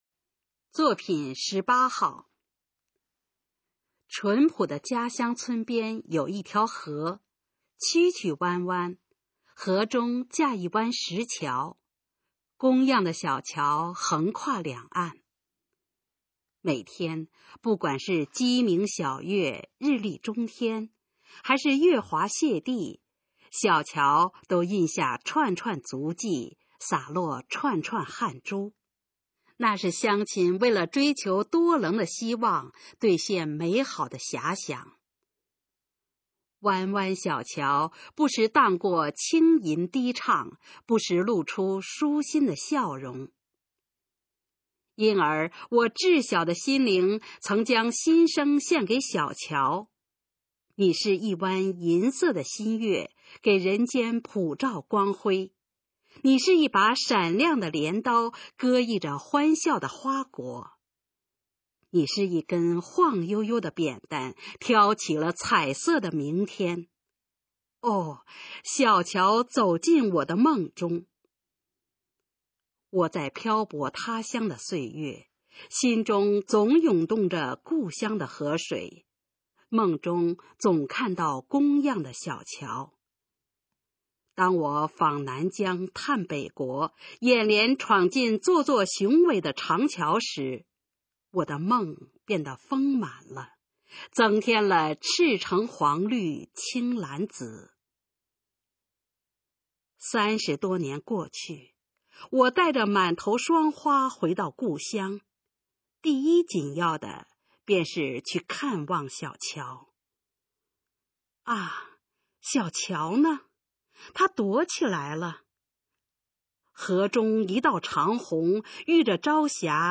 首页 视听 学说普通话 作品朗读（新大纲）
《家乡的桥》示范朗读_水平测试（等级考试）用60篇朗读作品范读